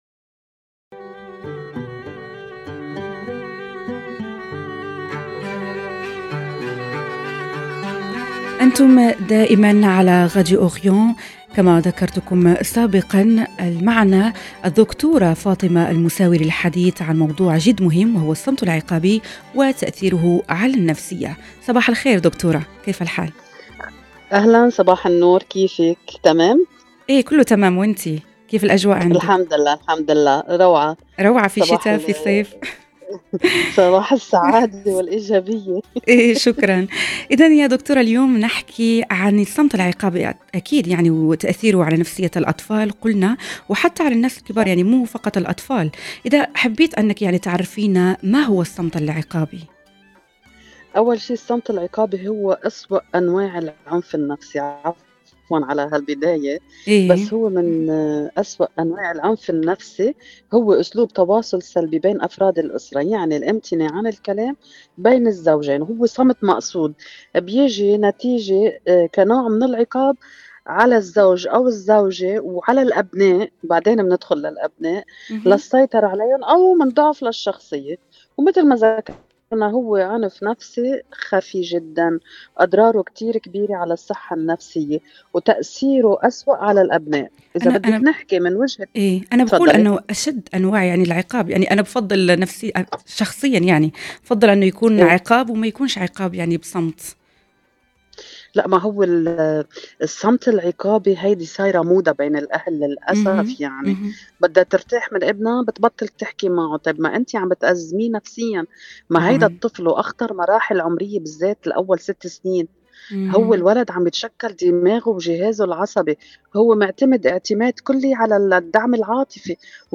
سوا من باريس لقاء